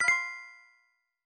tap.mp3